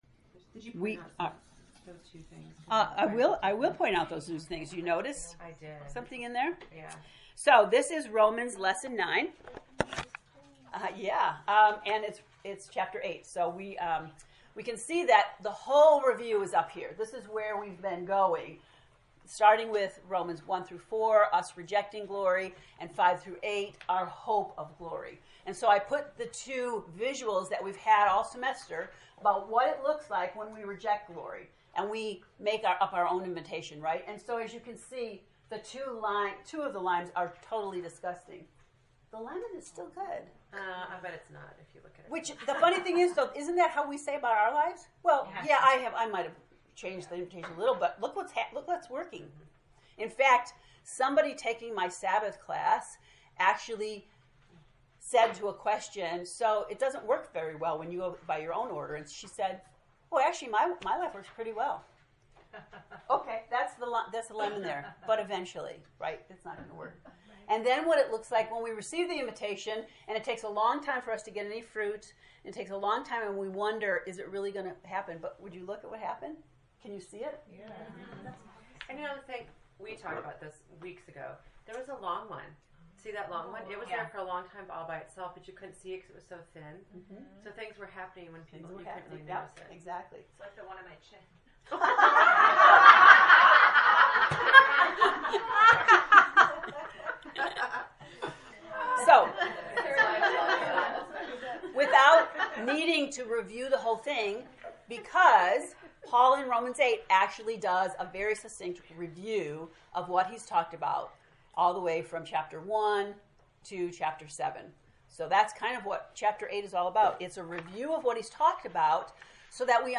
To listen to the lesson 9 lecture, ” The Continuing Evidence of God’s Faithfulness,” click below: